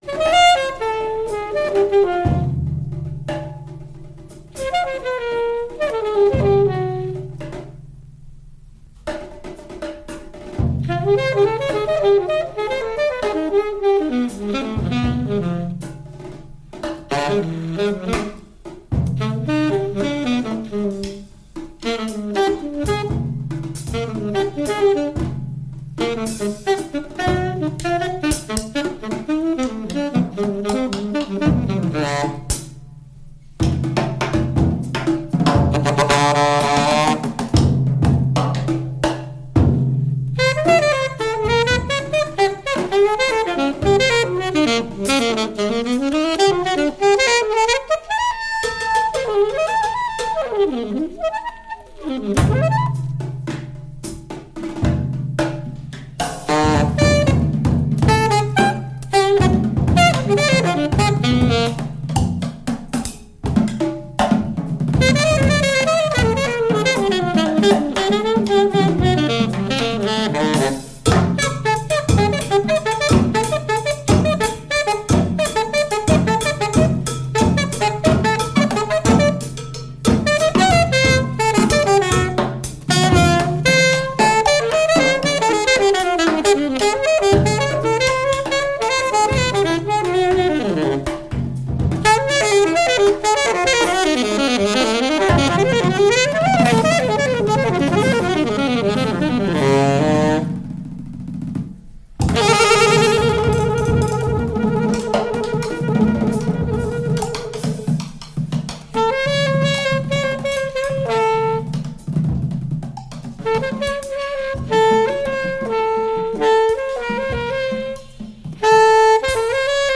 sax
piano
bass
drums